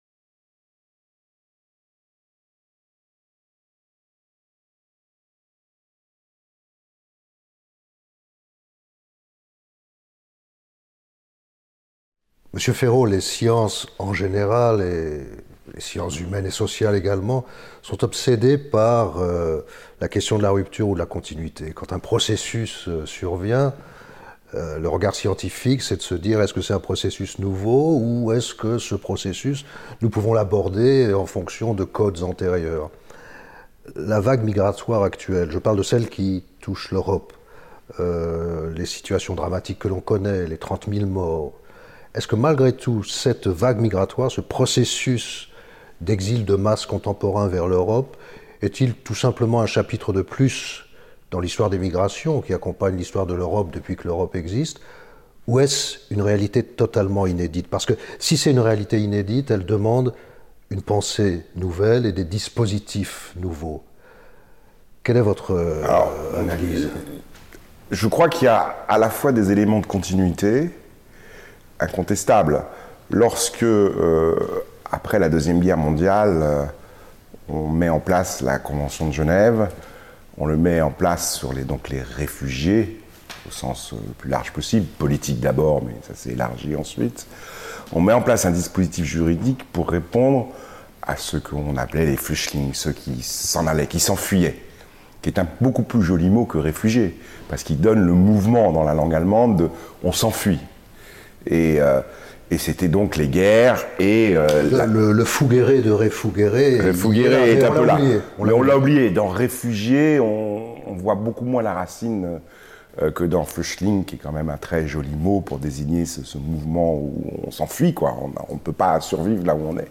La France et sa politique migratoire. Entretien